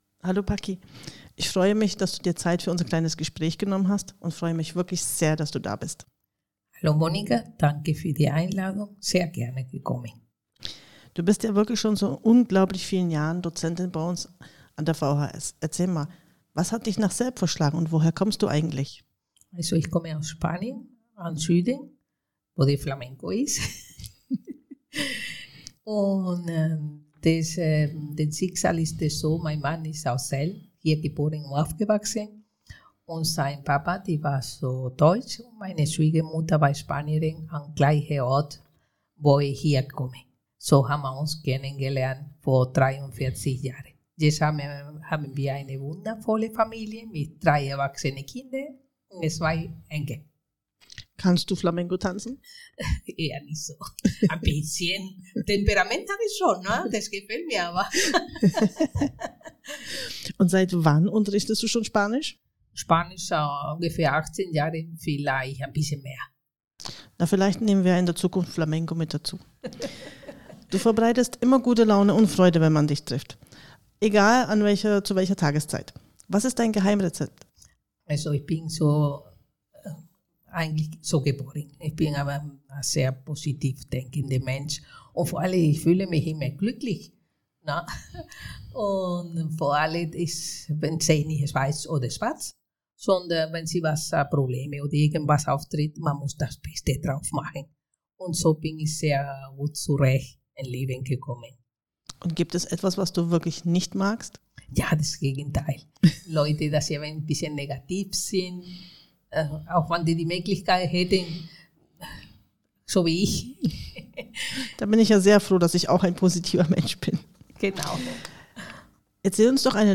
Sie berichten über ihren Werdegang, ihre Kurse und teilen am Ende eine kurze Geschichte in ihrer Muttersprache – ein besonderer Einblick in ihre Kultur und Sprache. Erfahren Sie, was sie motiviert, welche besonderen Erlebnisse sie im Unterricht hatten und was sie an ihrer Lehrtätigkeit begeistert.